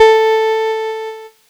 Cheese Note 03-A2.wav